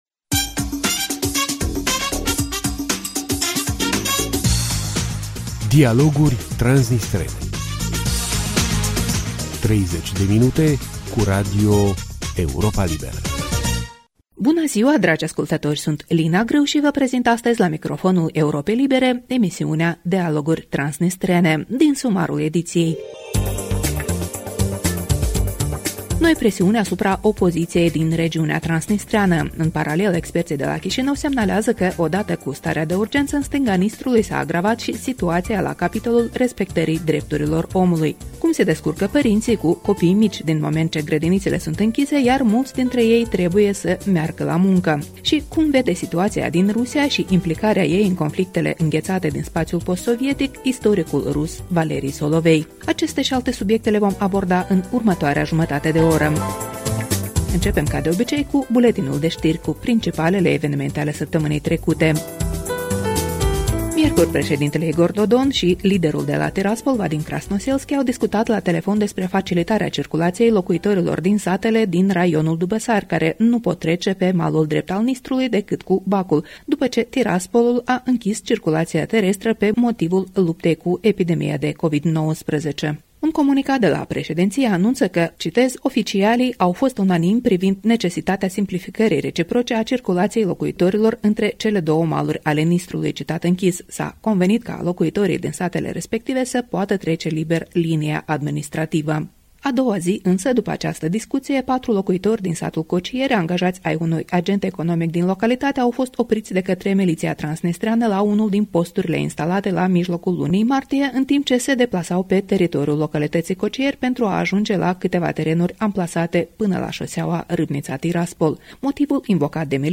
O emisiune moderată